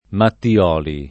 Mattiolo [matti-0lo] pers. m. stor. (= Mattia) — sim. i cogn. Mattioli [
matti-0li o mattL0li] e Mattiolo [matti-0lo o mattL0lo], con qualche tendenza a una perdita dell’iato, essendo poco sentita la derivaz. da Mattia